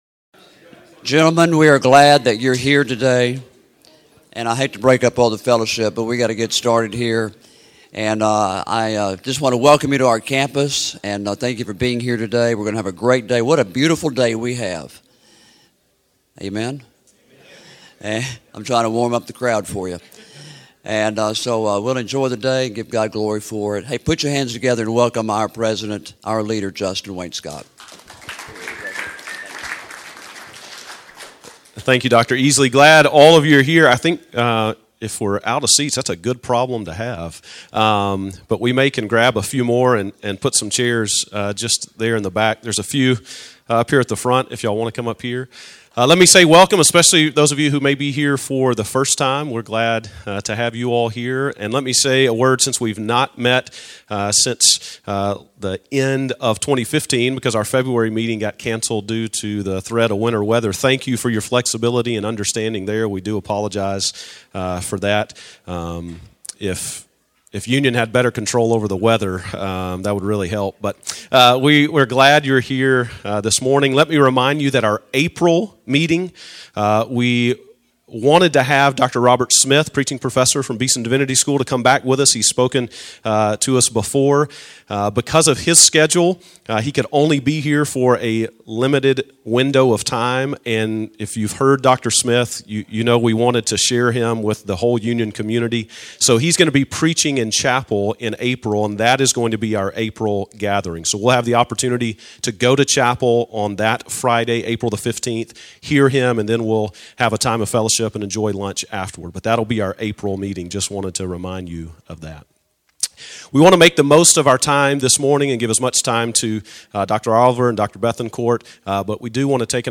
West Tennessee Pastors Conference: Religious Liberty Dialogue